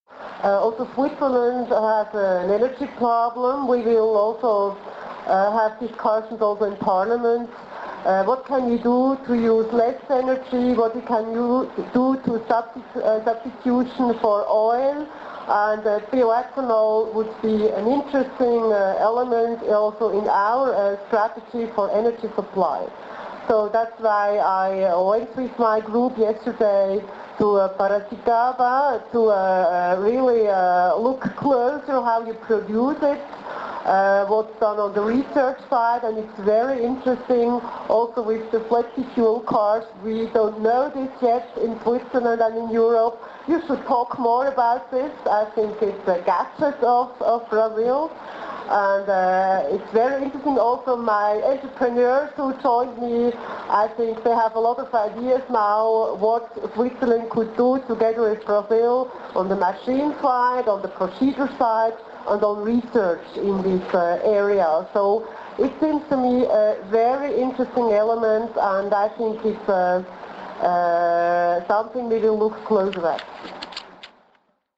A ministra da Economia, Doris Leuthard, fala do interesse pelo biocombustível.